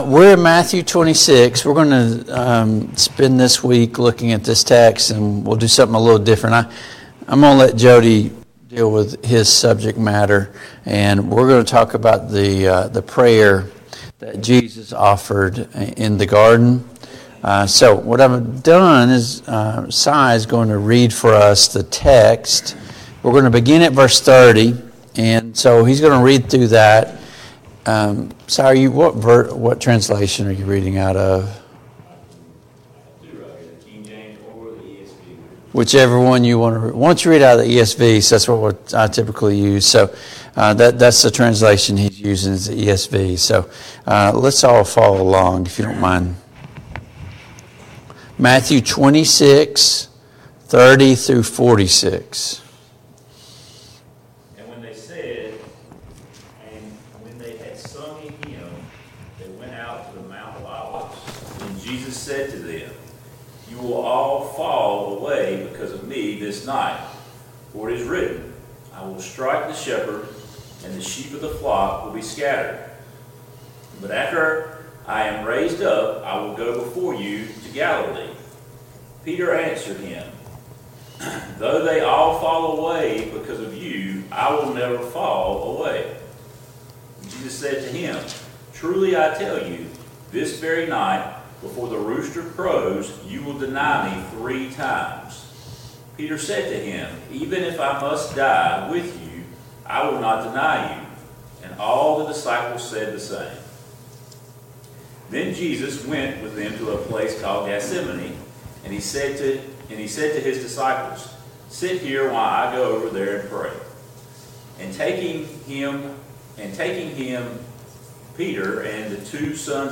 Passage: Matthew 26:26-30 Service Type: Sunday Morning Bible Class